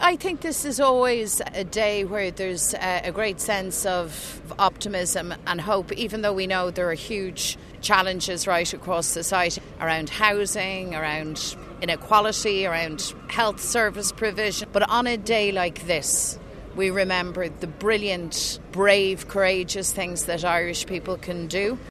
The 108th Anniversary event of the Rising was held outside the GPO in Dublin this afternoon led by the President, Taoiseach, and Táinaiste.
Mary Lou McDonald says it comes at a time when there is a real desire for political change: